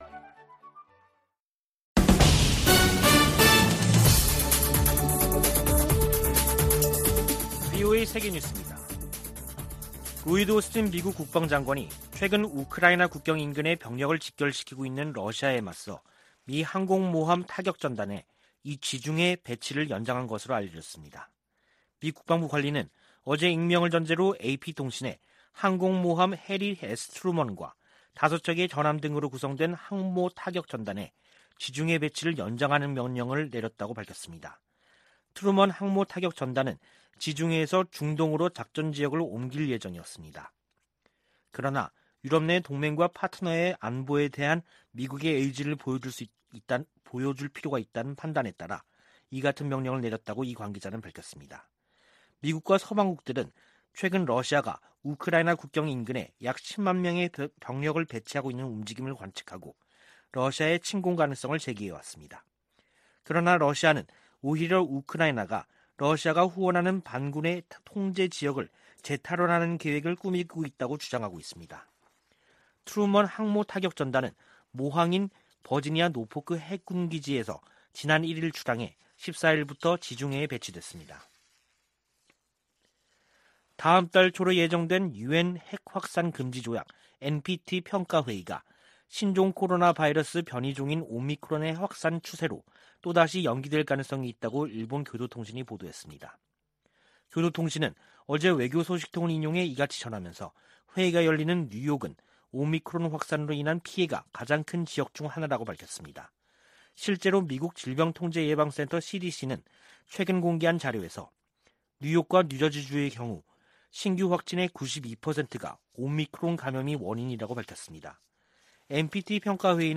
VOA 한국어 간판 뉴스 프로그램 '뉴스 투데이', 2021년 12월 29일 3부 방송입니다. 미 국무부는 한반도의 항구적 평화 달성을 위해 북한과의 외교에 전념하고 있다고 밝혔습니다. 정의용 한국 외교부 장관은 미-한 간 종전선언 문안 조율이 마무리됐다고 밝혔습니다. 조 바이든 미국 대통령이 역대 최대 규모 국방예산을 담은 2022국방수권법안에 서명했습니다.